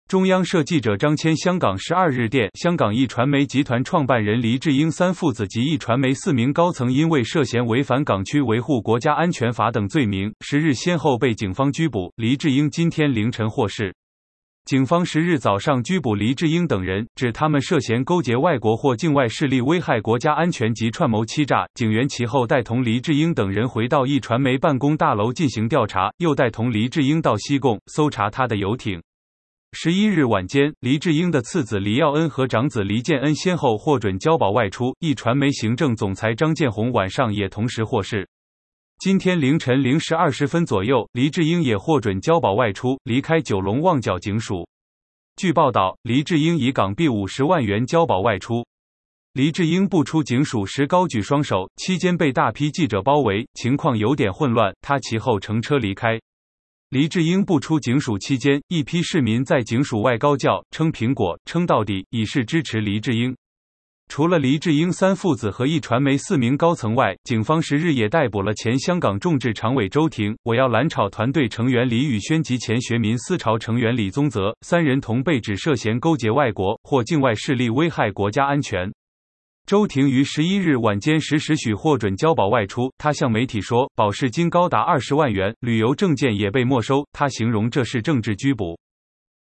黎智英步出警署时高举双手，期间被大批记者包围，情况有点混乱，他其后乘车离开。
黎智英步出警署期间，一批市民在警署外高叫“撑苹果，撑到底”，以示支持黎智英。